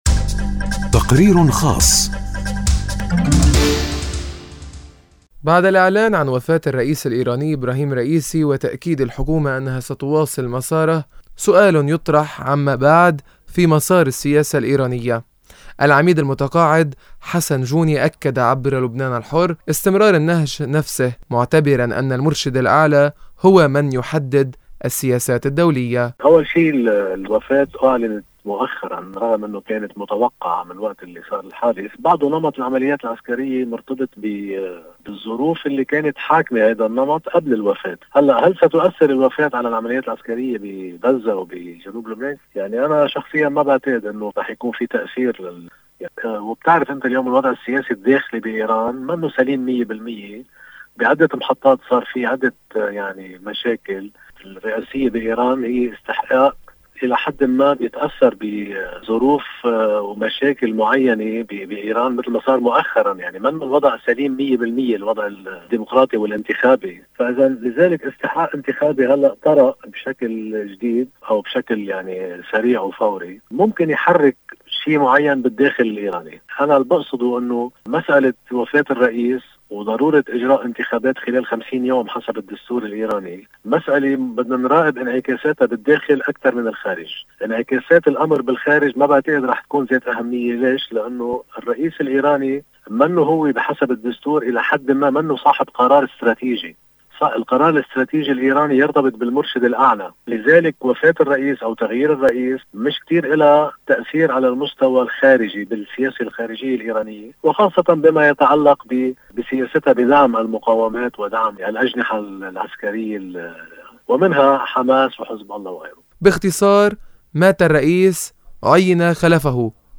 باختصار مات الرئيس عين خلفُهُ، والأرجح أن تبقى سياسة إيران على حالِها. التقرير